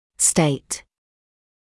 [steɪt][стэйт]утверждать; заявлять; состояние, положение